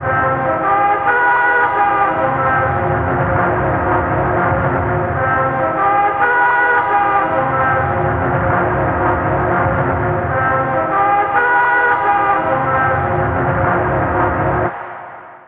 093 Distorted Trumpets-C.wav